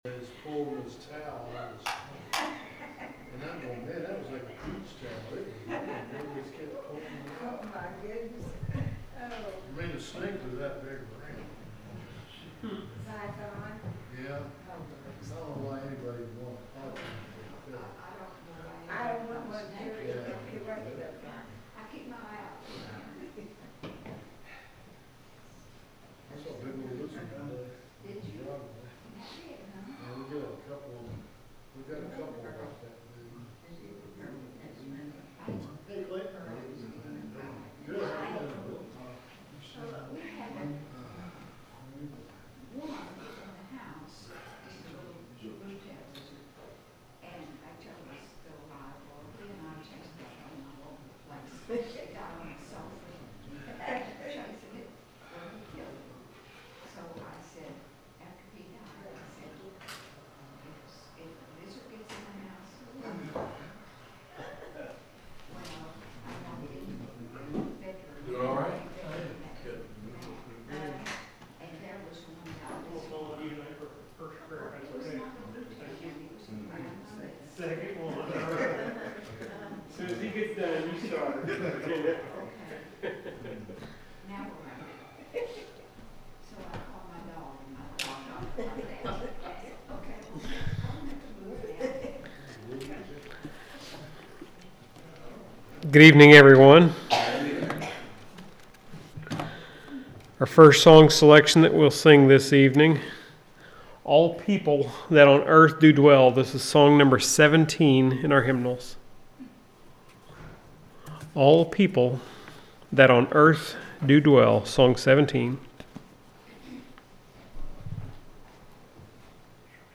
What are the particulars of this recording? The sermon is from our live stream on 4/23/2025